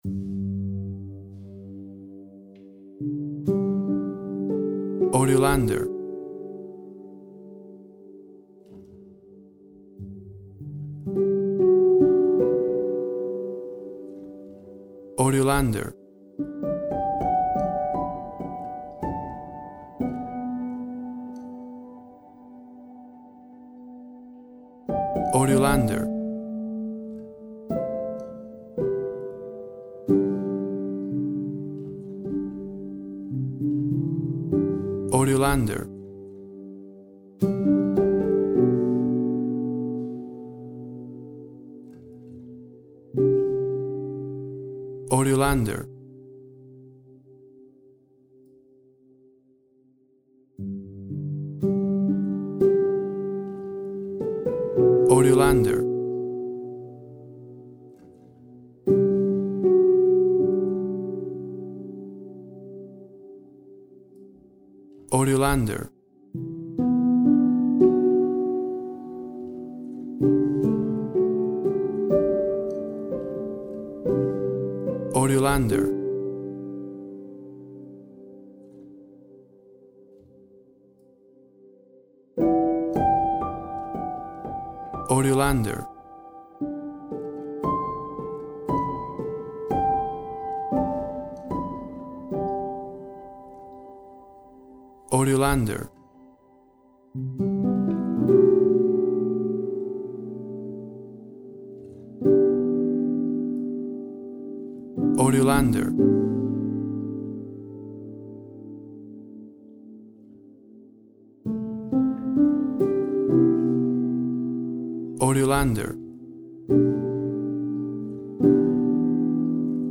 Calm and reflective piano music